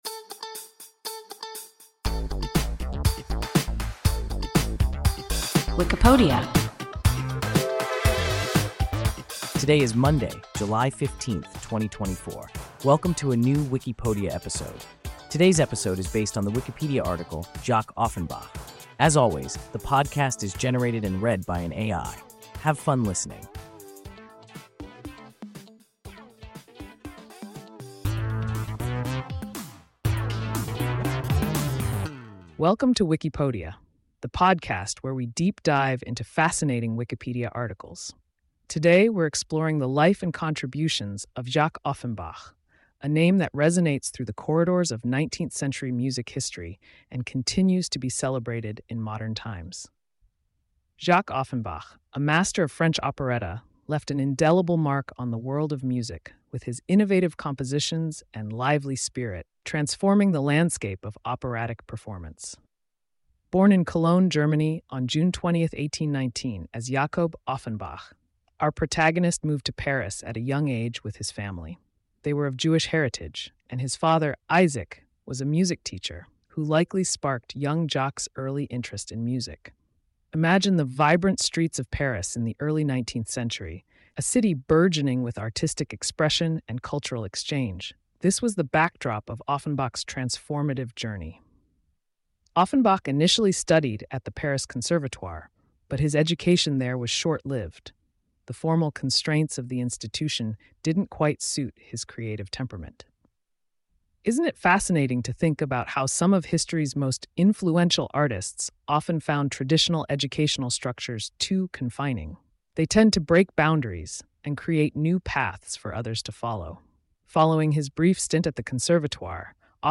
Jacques Offenbach – WIKIPODIA – ein KI Podcast